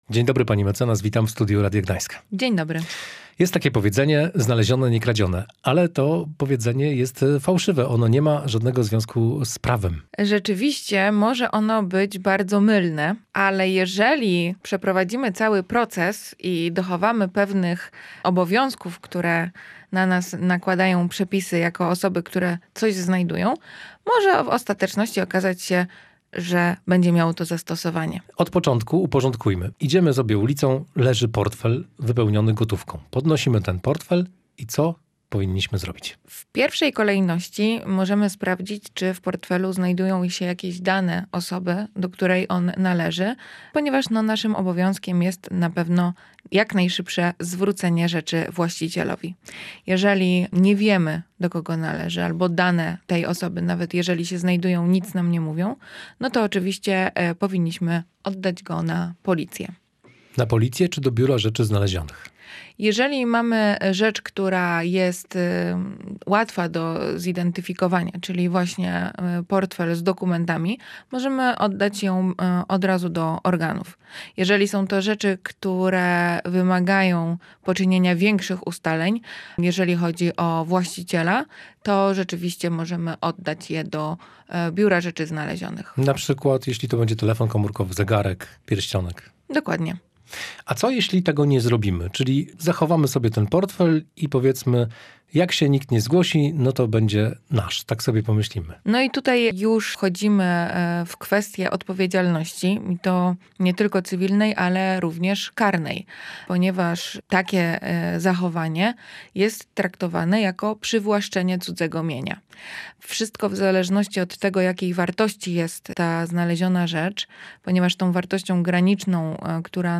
O tym w audycji „Prawo na co dzień” rozmawiali adwokat